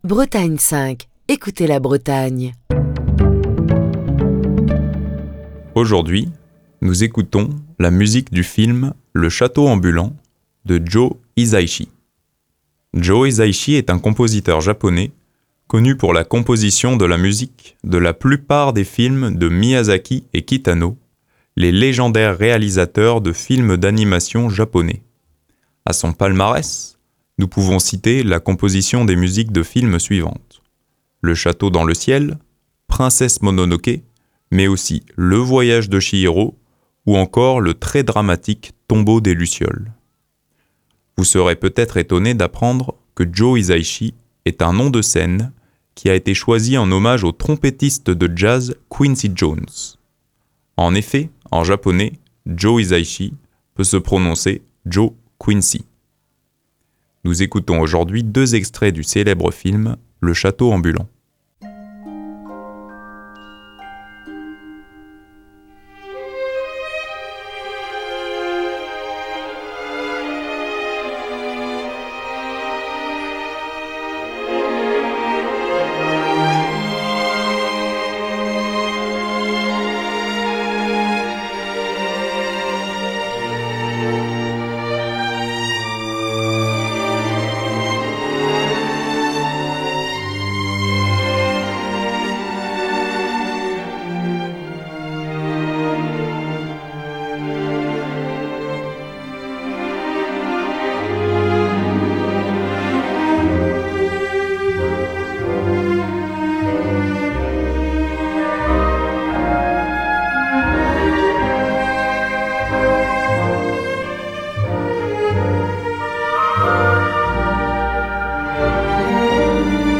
plein de lyrisme
mélancolique